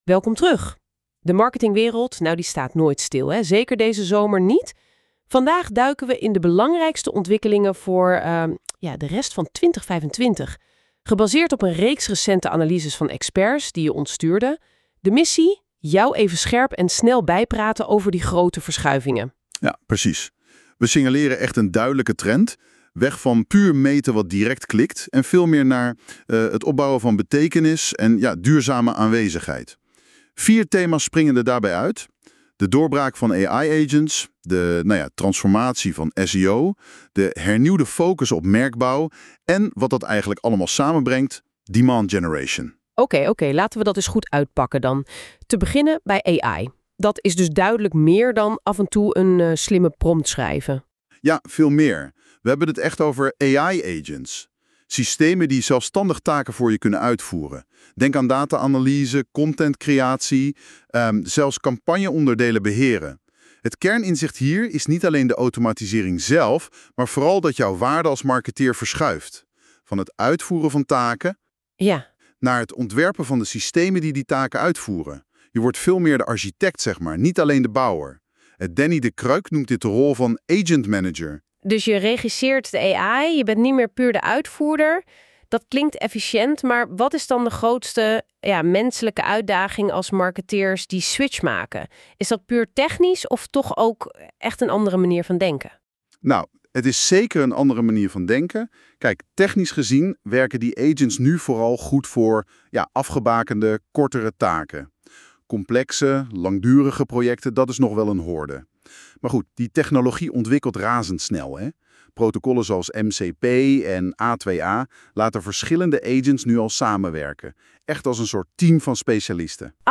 Luister je liever dan je leest? Laat je door 2 AI-hosts in 7 minuten bijpraten over deze summerread, gegenereerd door NotebookLM.